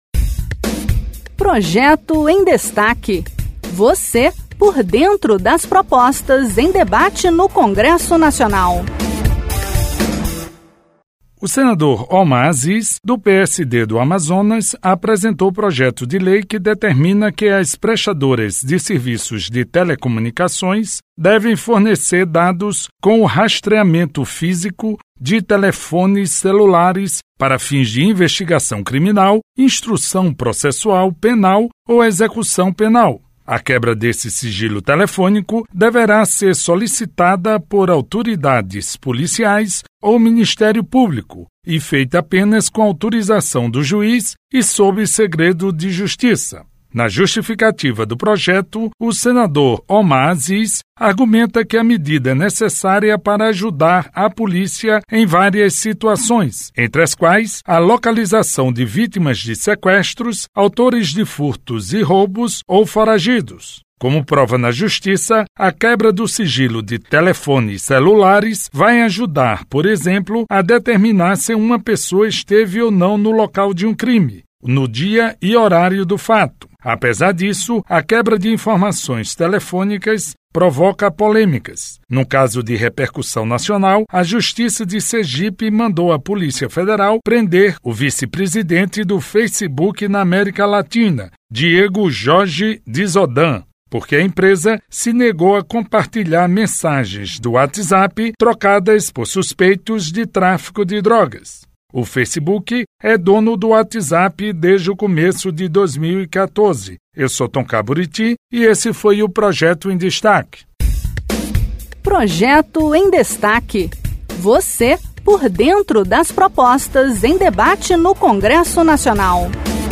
De acordo com o texto, as prestadoras de serviços de telecomunicações serão obrigadas a fornecer os dados quando o pedido for feito por autoridades policiais ou pelo Ministério Público, com autorização judicial e sob segredo de Justiça. Confira os detalhes da proposta na reportagem